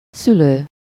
Ääntäminen
US : IPA : [ˈpɛɹ.ənt]